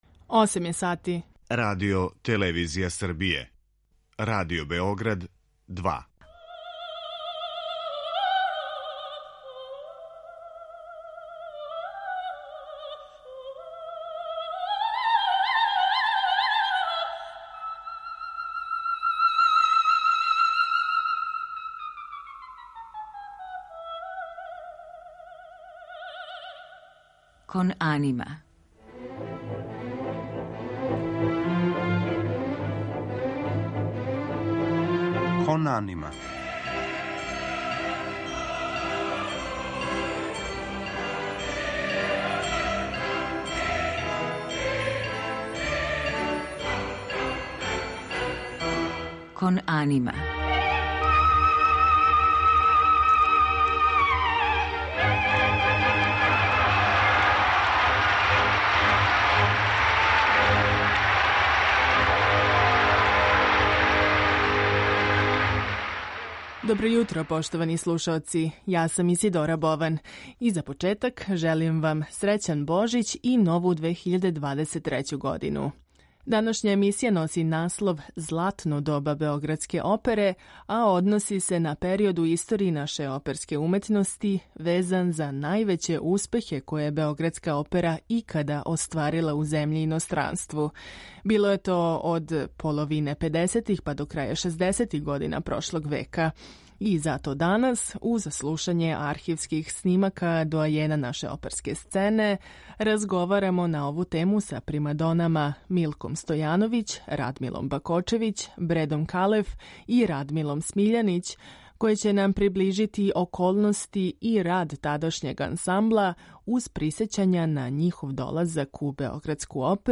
Термин „Златно доба" везан је за период од половине педесетих до краја шездесетих година прошлог века, односно највеће успехе у историји домаће оперске уметности које је Београдска опера икада остварила у земљи и иностранству. Нова Кон анима враћа сећања на долазак познатих примадона у Народно позориште, рад тадашњег ансамбла и остварене турнеје у иностранству, уз архивске снимке великана из опера Дон Кихот Жила Маснеа, Моћ судбине Ђузепа Вердија, Борис Годунов Модеста Мусоргског, као и Мазепа и Пикова дама Петра Чајковског.